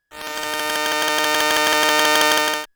ihob/Assets/Extensions/RetroGamesSoundFX/Hum/Hum06.wav at master
Hum06.wav